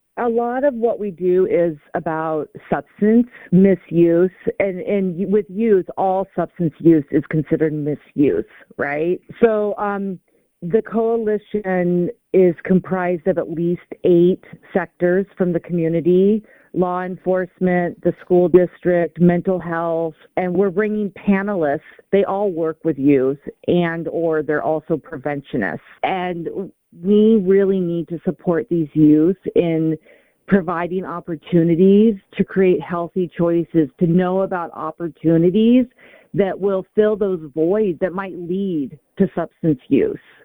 We spoke with her about the coalition and its goals.